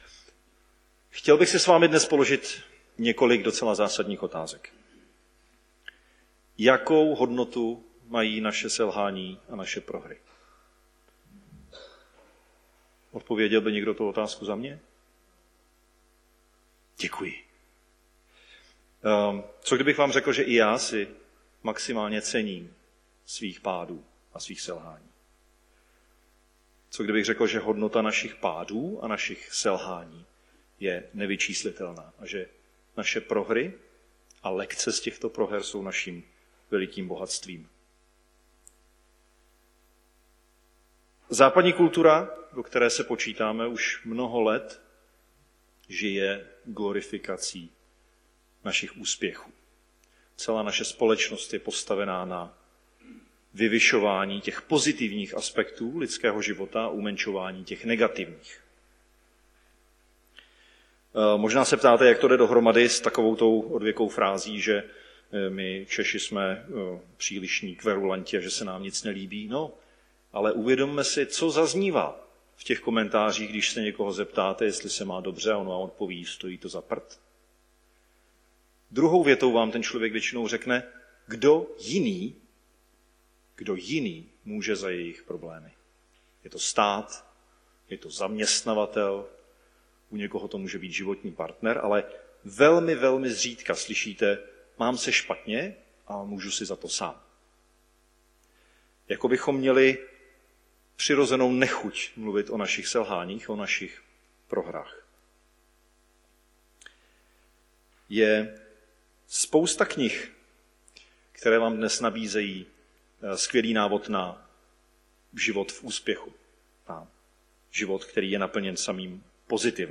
Nedělní bohoslužby Husinec přehrát